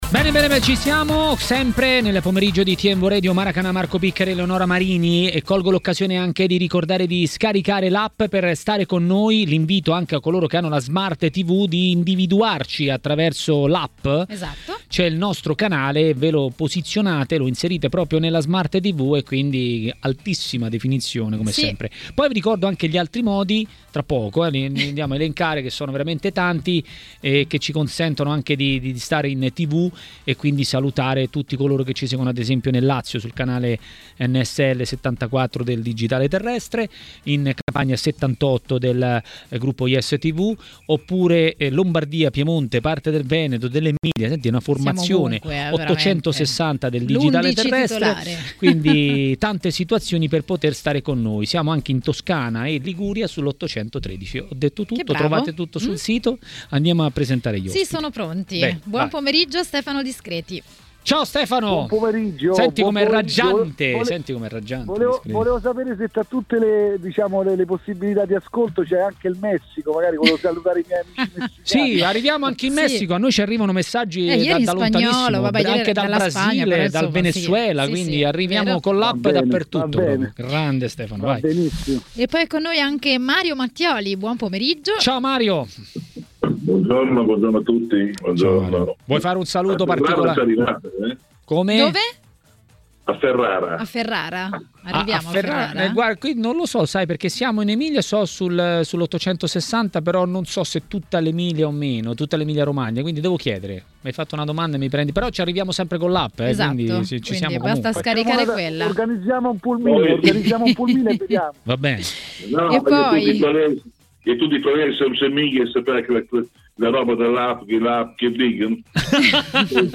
a TMW Radio, durante Maracanà, ha parlato dei temi del giorno.